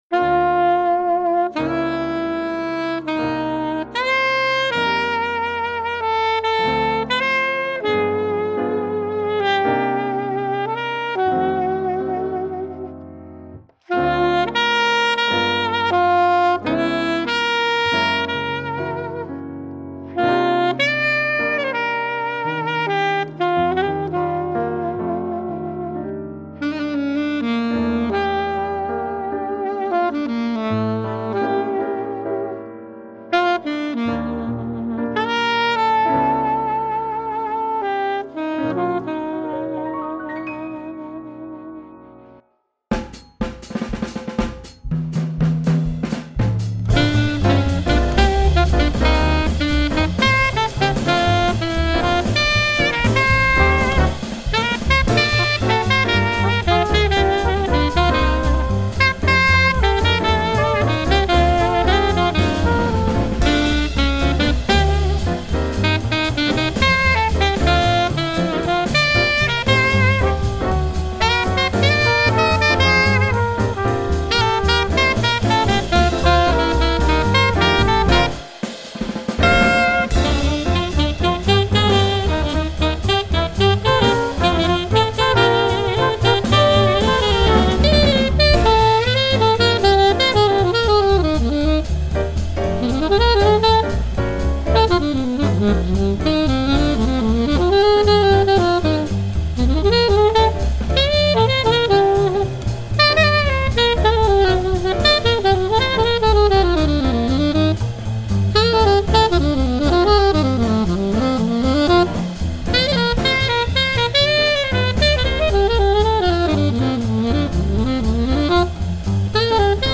jazz album
The musicians and sound quality are wonderful.